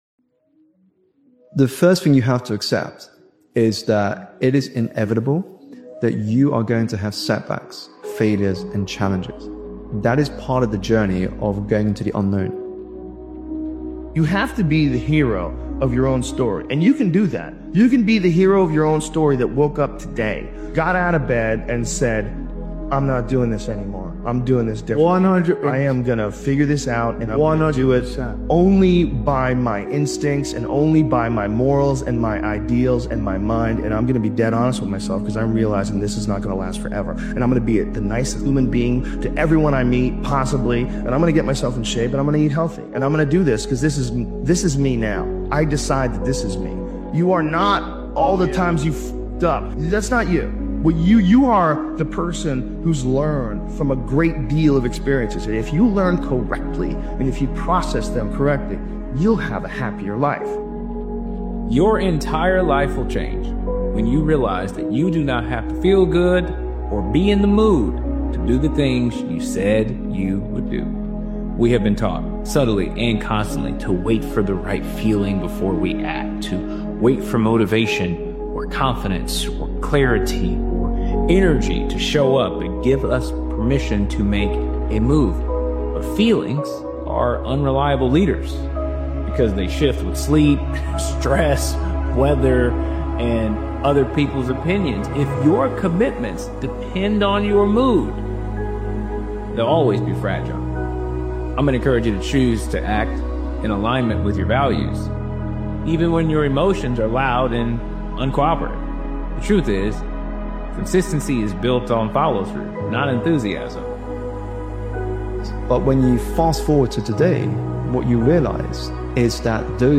Powerful Motivational Speech is a fierce and introspective motivational speech created and edited by Daily Motivations. This powerful motivational speeches compilation dives into the internal battles-fear, doubt, anger, ego-that either control you or get controlled by you.